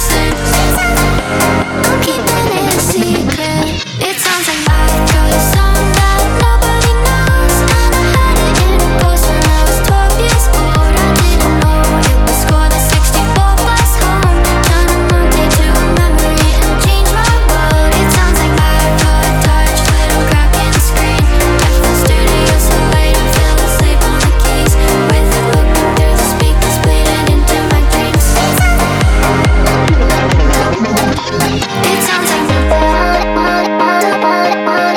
2025-06-20 Жанр: Танцевальные Длительность